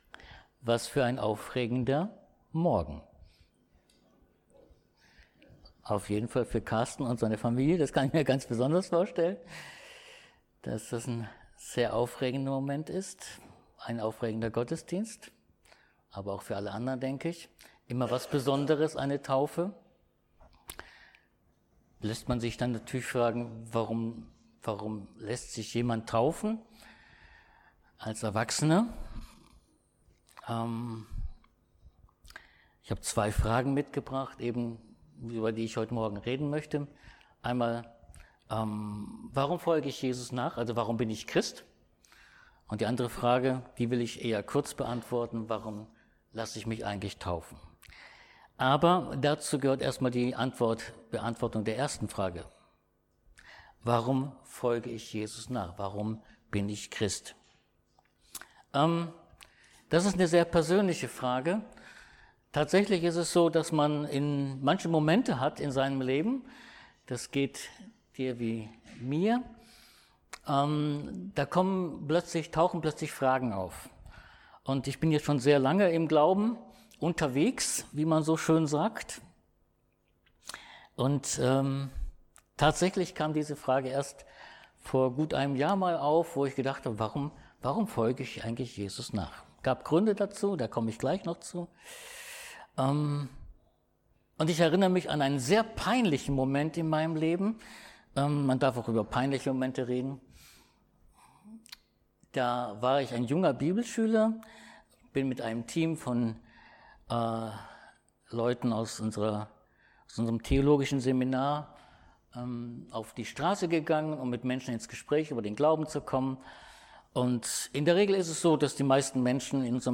Johannes 3;5-6 Dienstart: Predigt Jeder Christ sollte wissen